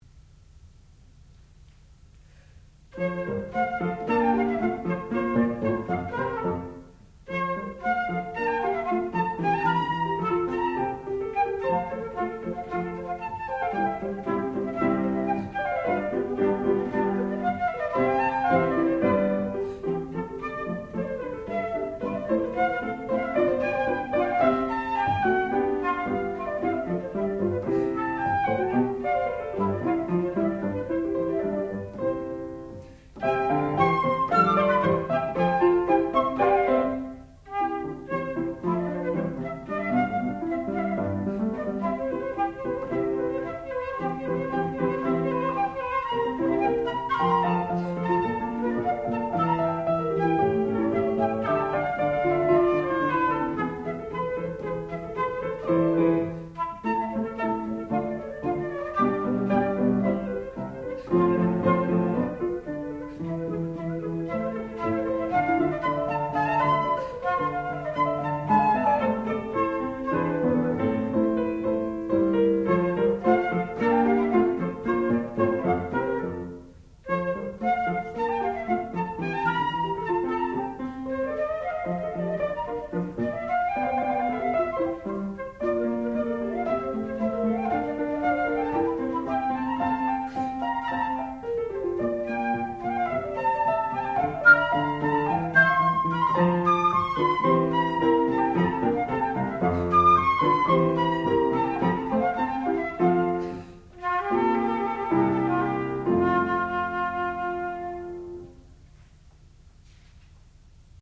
Sonata for flute and piano
sound recording-musical
classical music
Advanced Recital